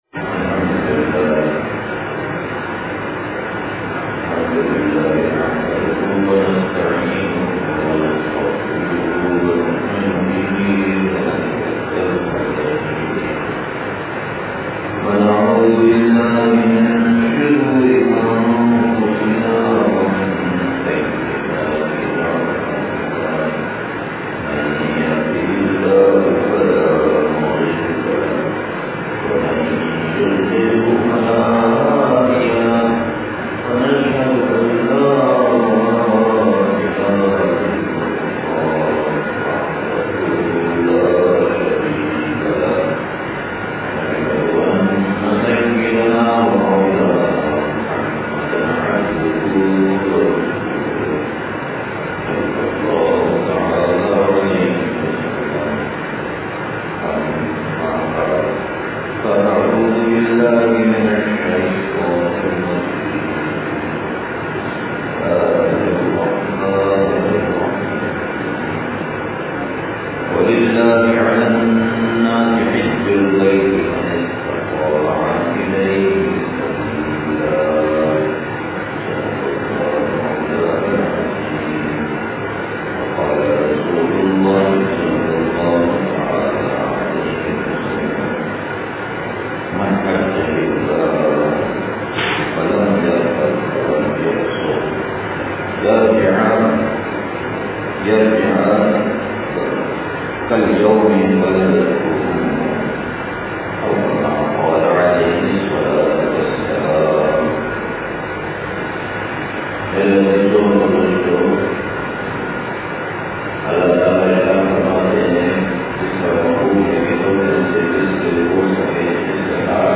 بیان جمعہ مسجد ابو بکر و مدرسہ مرکز امداد و اشرف نزد معمار ہاؤسنگ کراچی